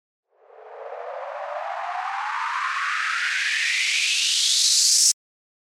FX-991-RISER
FX-991-RISER.mp3